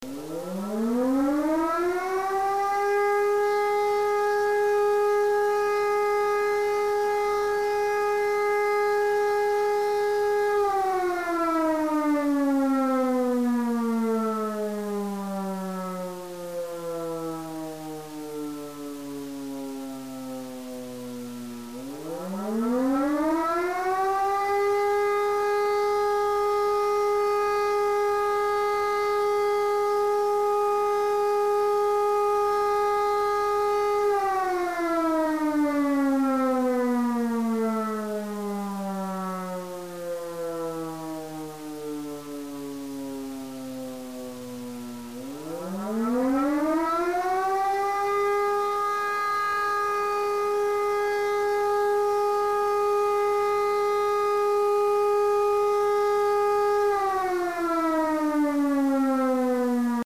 Sirenenton
30.04.2006 MP3-Klingelton einer Feuerwehrsirene
sirene2.mp3